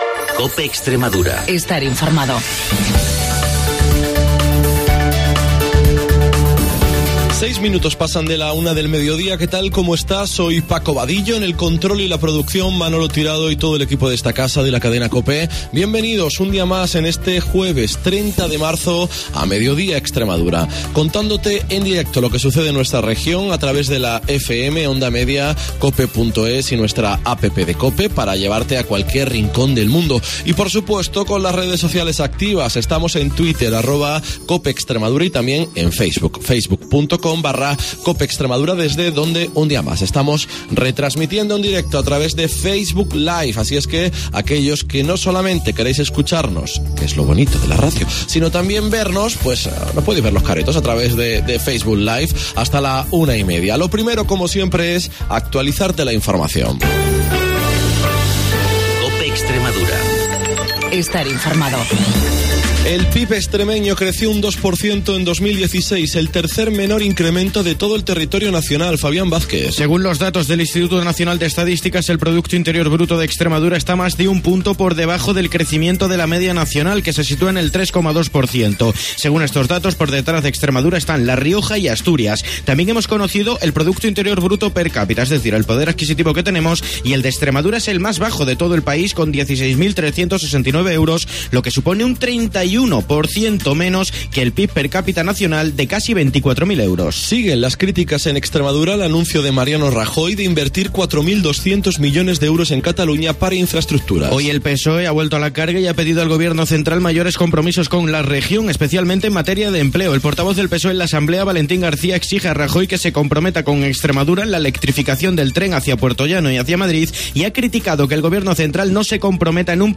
Hemos conectado en directo con el hospital Virgen de la Montaña de Cáceres donde se ha producido un incendio que ha obligado a desalojar parte del centro hospitalario.